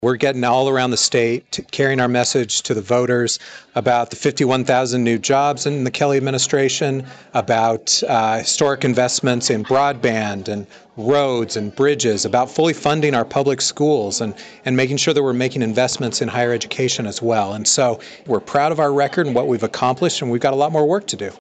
Kansas Lt. Governor David Toland made a campaign stop in Emporia Monday evening.